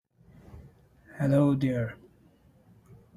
indian
male